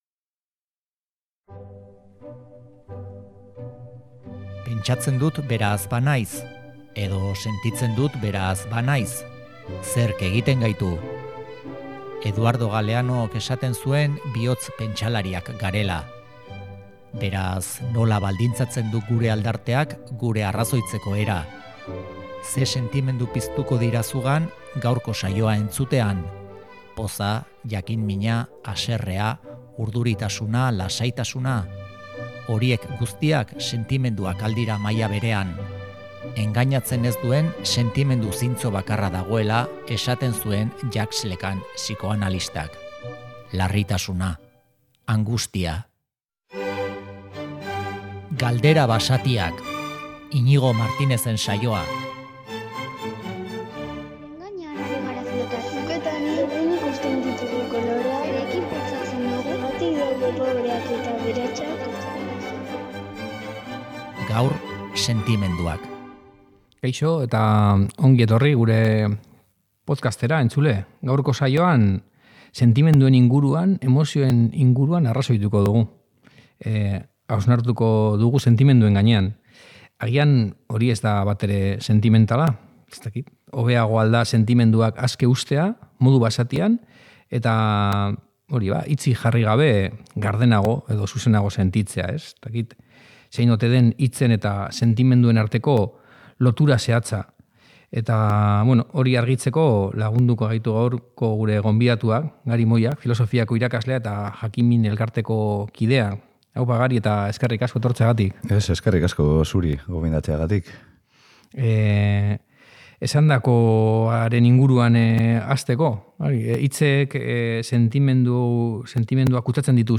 Haur filosofiari buruzko podcasta da Galdera Basatiak.